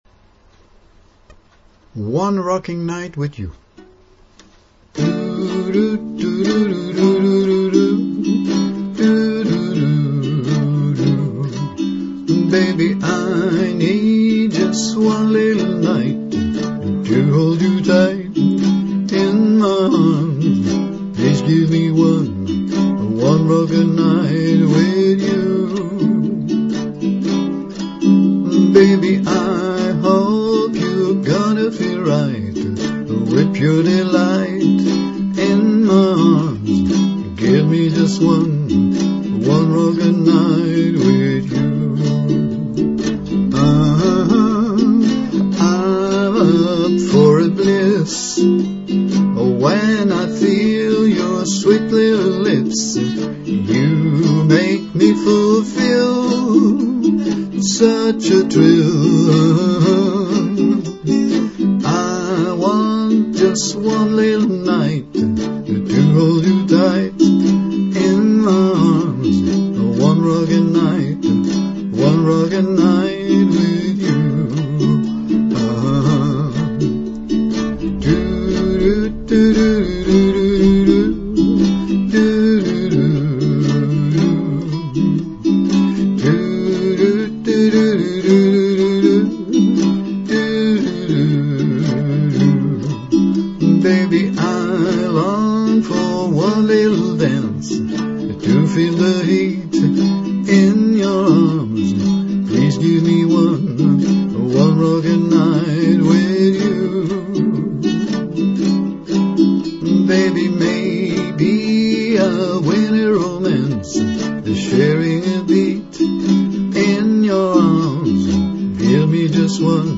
onerockingnightwithyouuke.mp3